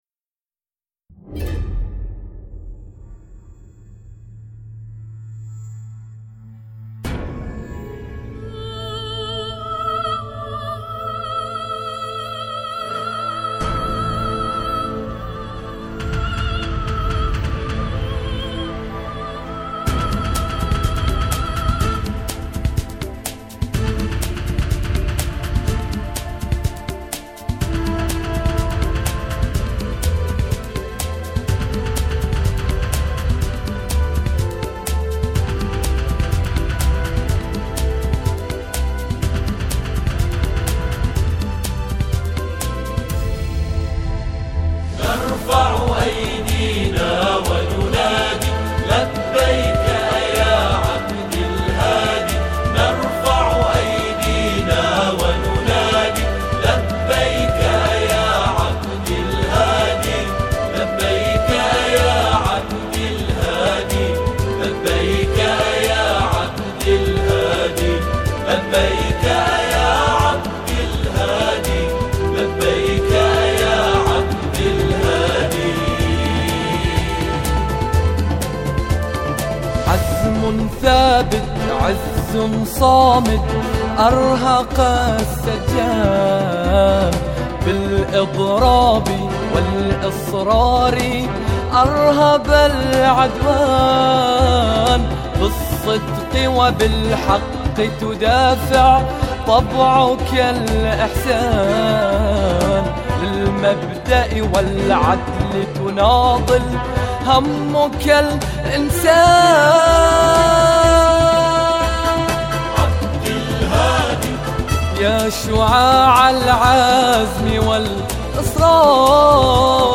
أناشيد بحرينية